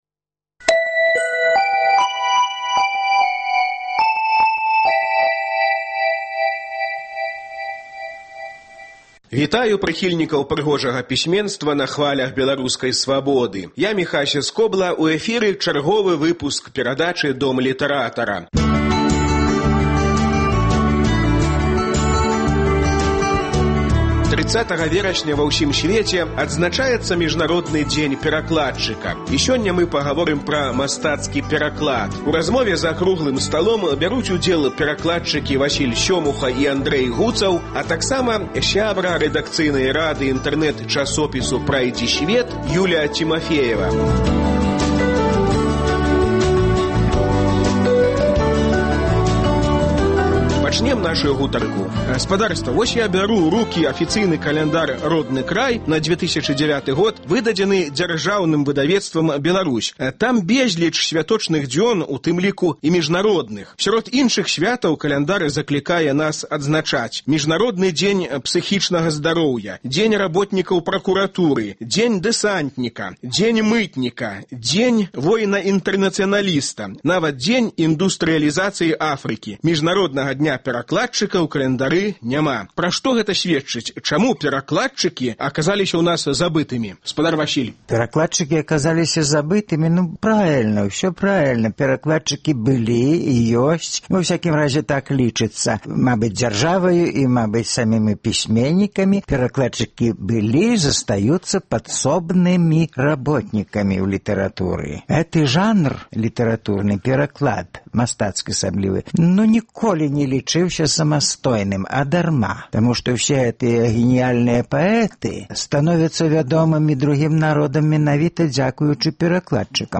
30 верасьня ва ўсім сьвеце адзначаецца Міжнародны дзень перакладчыка. З гэтай нагоды ў "Доме літаратара" круглы стол пра мастацкі пераклад.